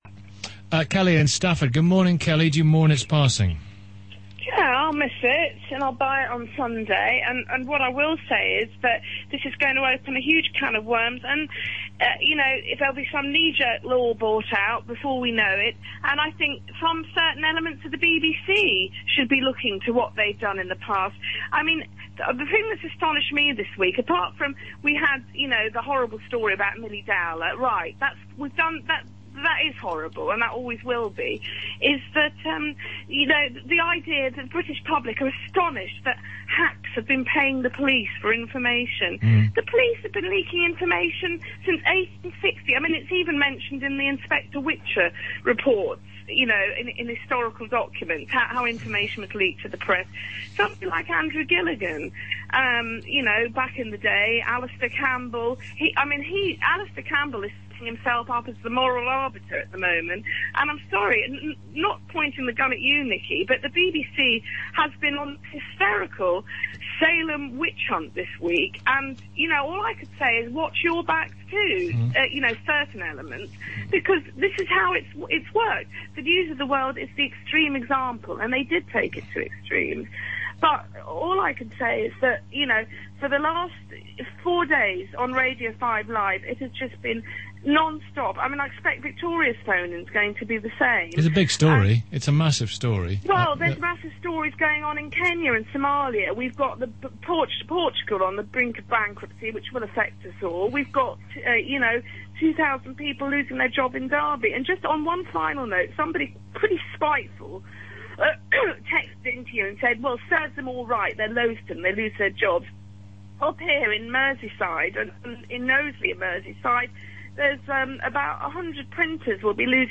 Nicky Campbell Phone-in, R5L